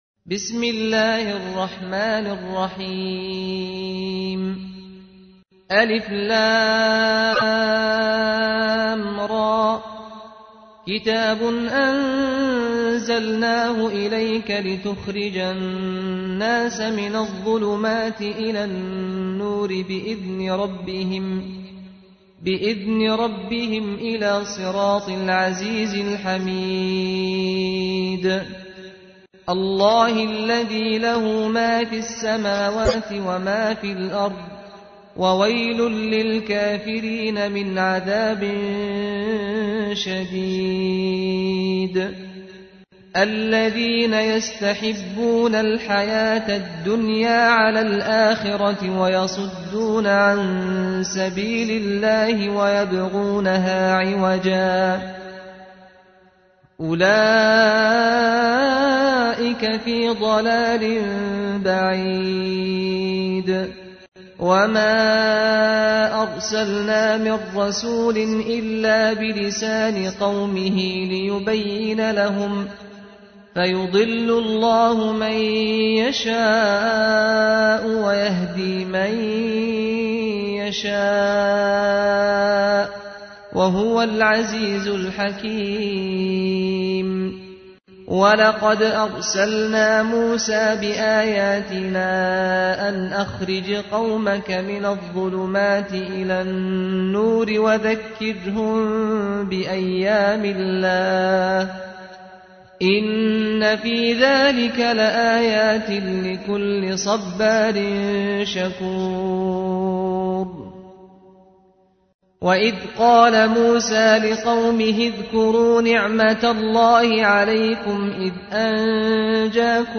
تحميل : 14. سورة إبراهيم / القارئ سعد الغامدي / القرآن الكريم / موقع يا حسين